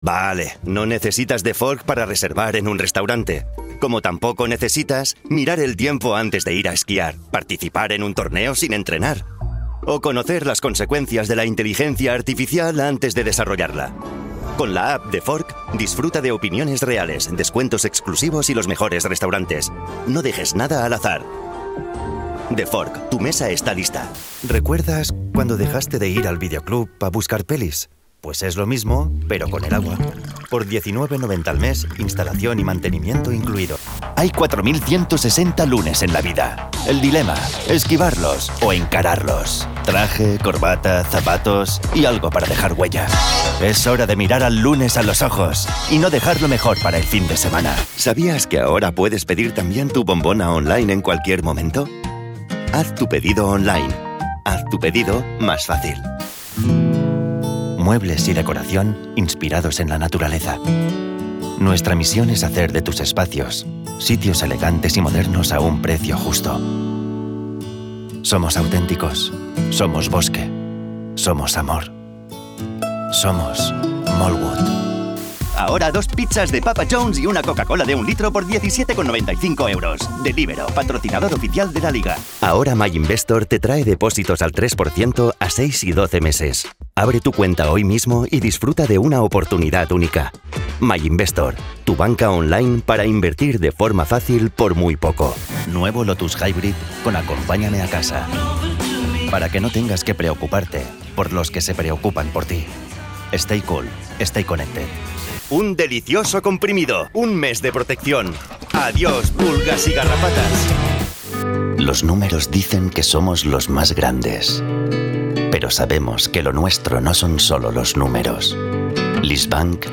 Jeune, Accessible, Fiable, Mature, Amicale
Vidéo explicative
Sa voix est dynamique et jeune, mais il peut également la moduler vers des tons plus sérieux et chaleureux.